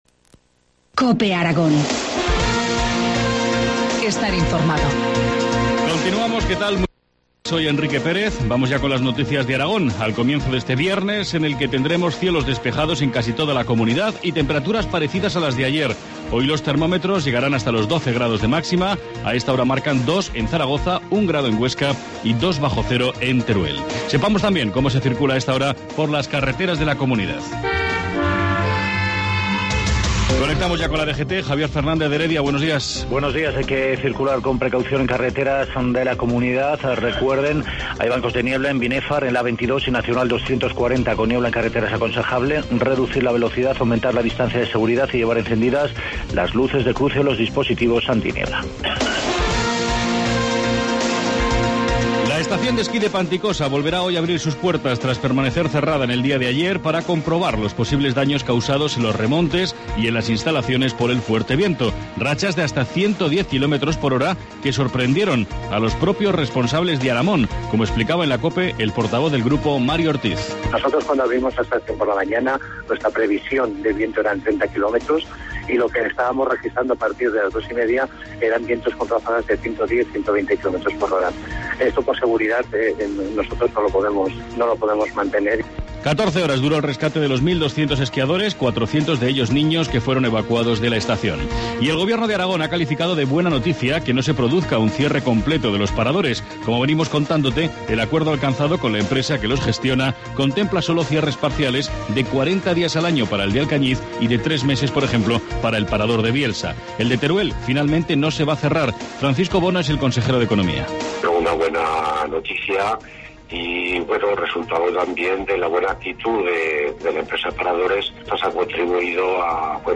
Informativo matinal, viernes 4 enero, 7,25 horas